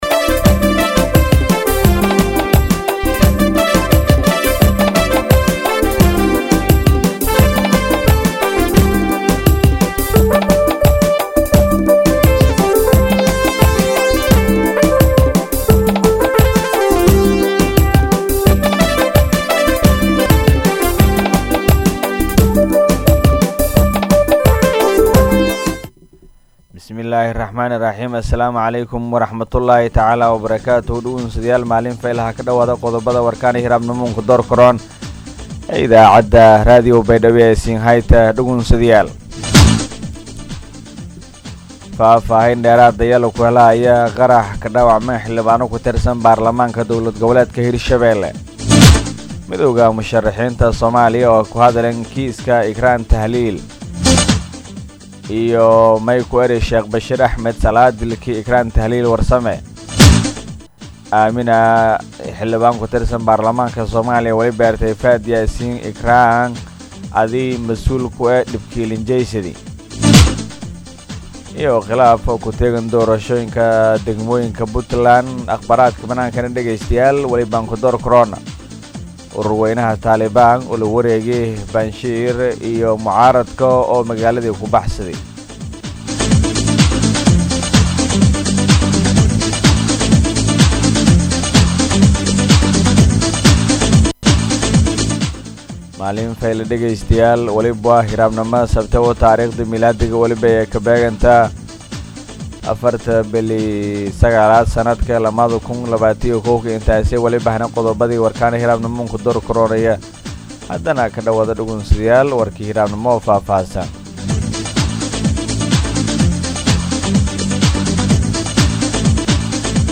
DHAGEYSO:-Warka Subaxnimo Radio Baidoa 4-9-2021